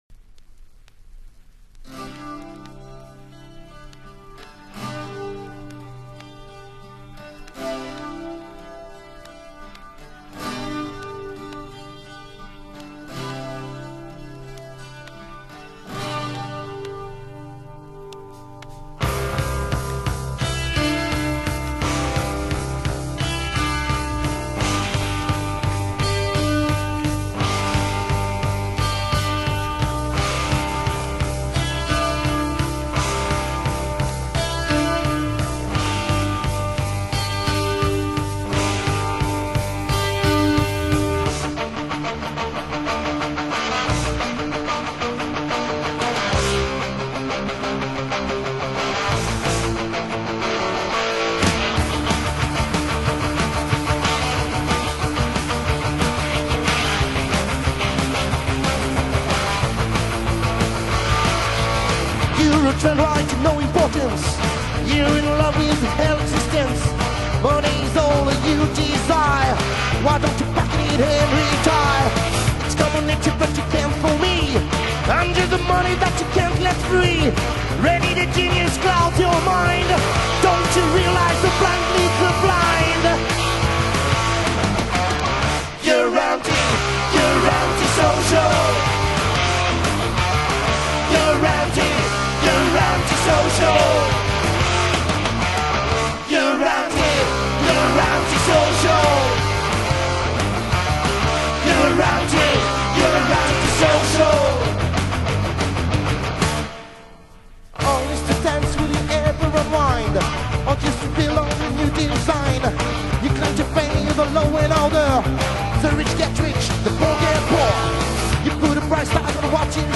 English version